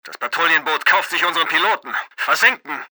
Voice-Over Recordings
For the part of Trevor, Effective Media was able to engage famous voice-over actor Martin Kessler, who synchronises big Hollywood names such as Vin Diesel and Nicolas Cage.